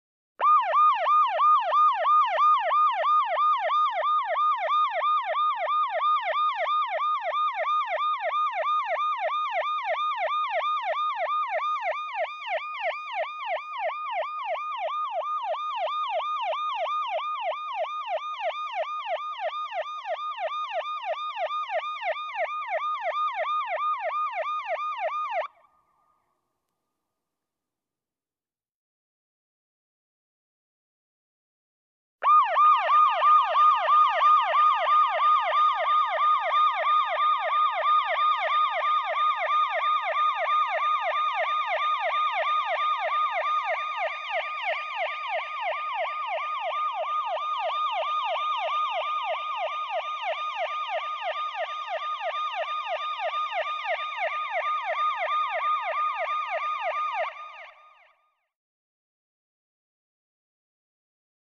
На этой странице вы можете скачать и послушать онлайн различные звуки сирены скорой помощи.
Звук сирены скорой помощи